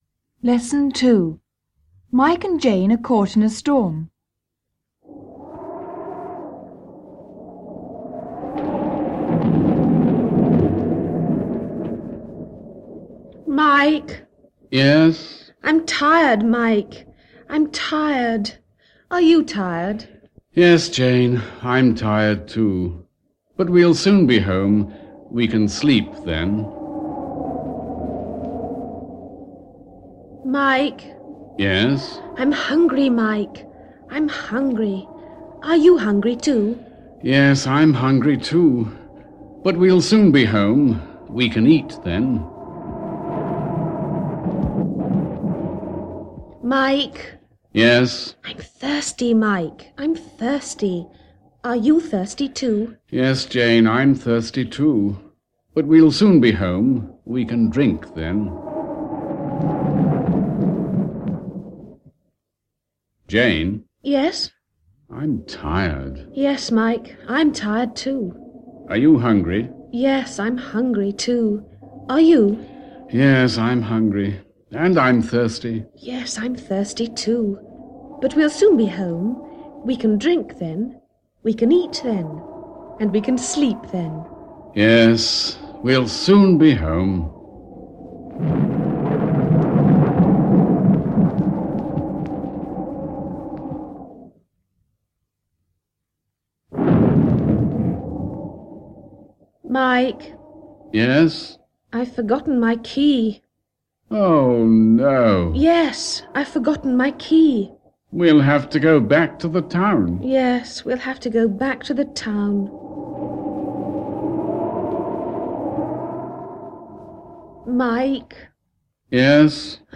Dialogo: Are you tired and are you hungry?
Un uomo e una donna rientrano a casa nel bel mezzo di un temporale.